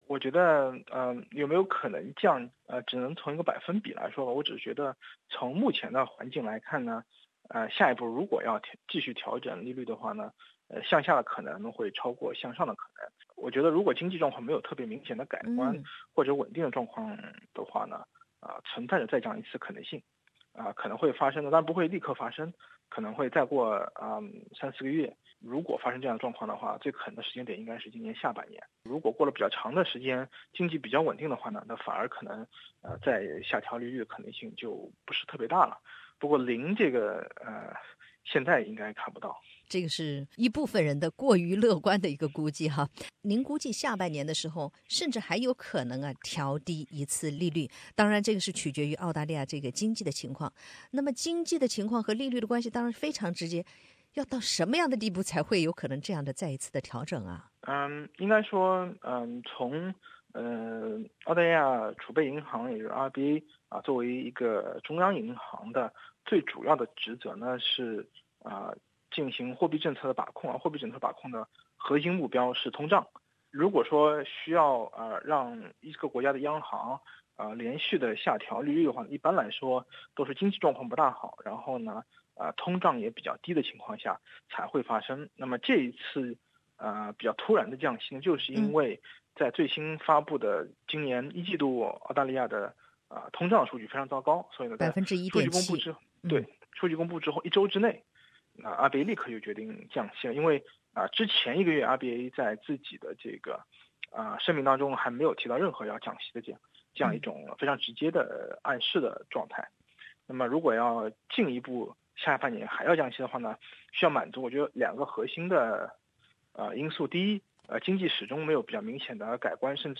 利率与房产市场行情并不直接发生关系。 请听采访录音。